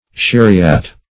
Sheriat \Sher"i*at\, n.